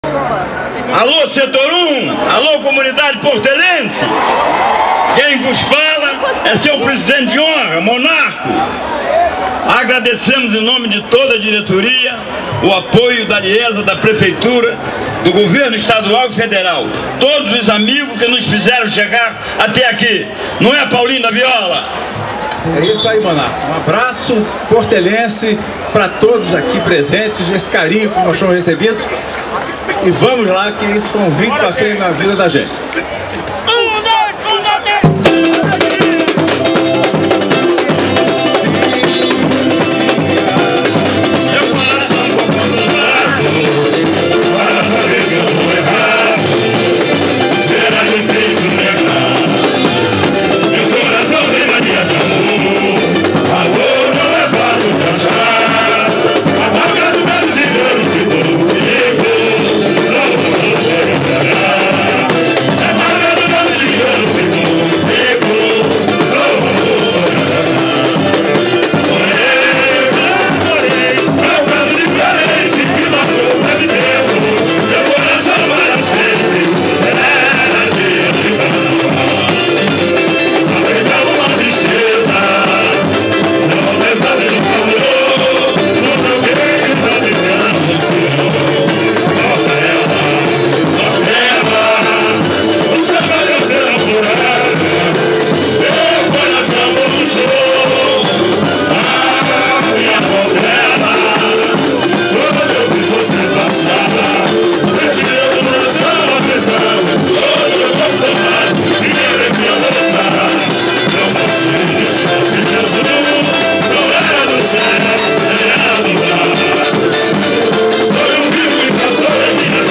21) A Portela voltou a esquentar com “Foi Um Rio que Passou em Minha Vida” após 16 anos. O samba foi cantado por Monarco e Paulinho da Viola (abaixo, o áudio extraído do YouTube);
Paulinho-da-Viola-e-Monarco-comandam-o-esquenta-da-Portela.mp3